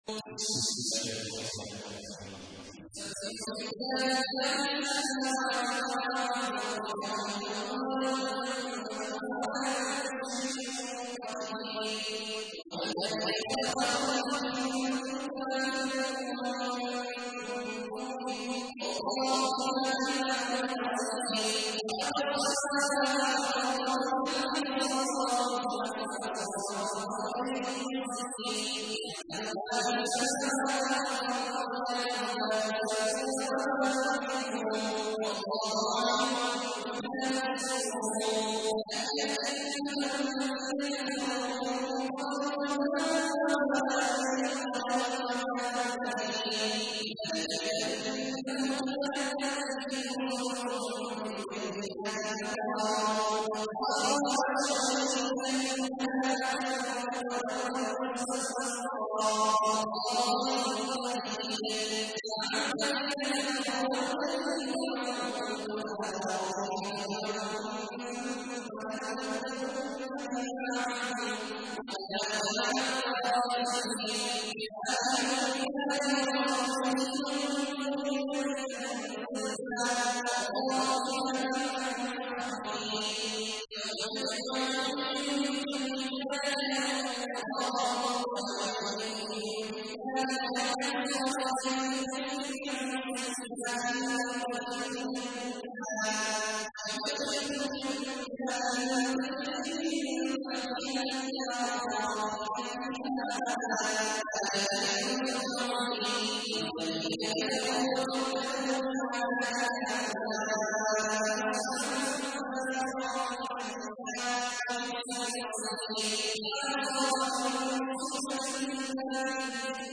تحميل : 64. سورة التغابن / القارئ عبد الله عواد الجهني / القرآن الكريم / موقع يا حسين